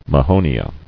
[ma·ho·ni·a]